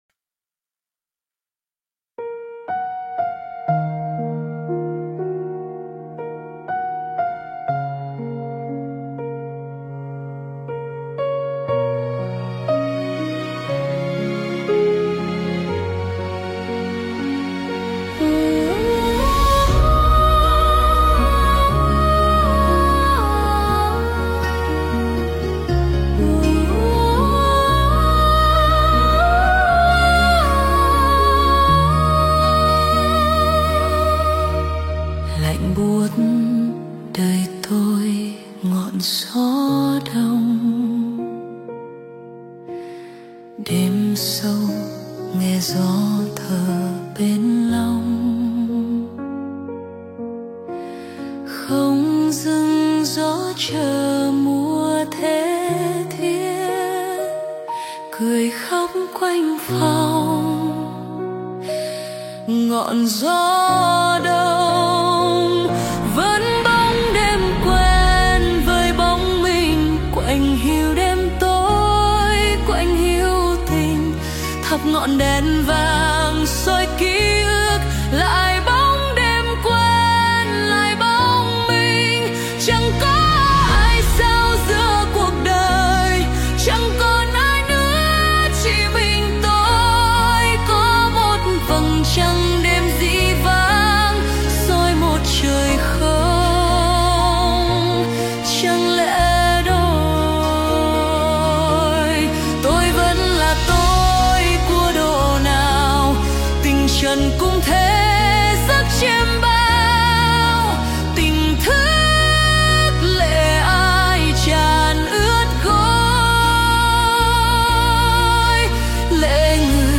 Phổ nhạc: Suno AI